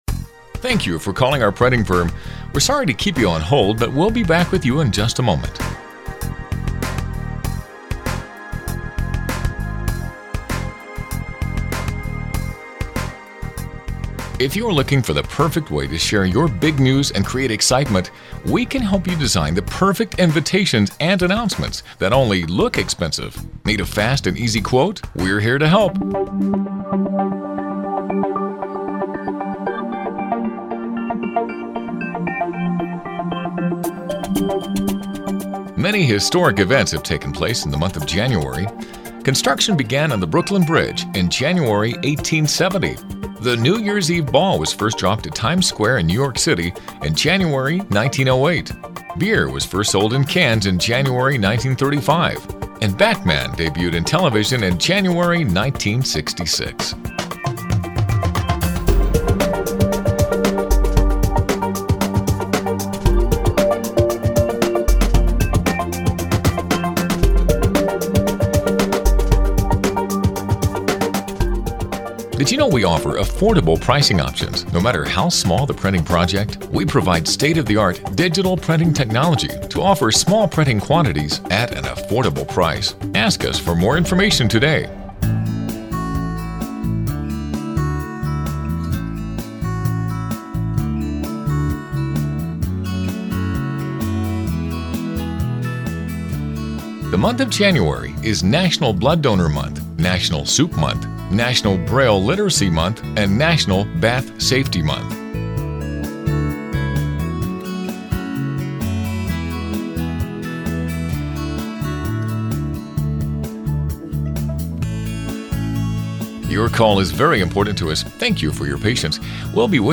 • Each month, we’ll provide you with two all-new, professionally produced advertising and music on hold audio files.
Male Voice Sample
advertising-on-hold-male-sample.mp3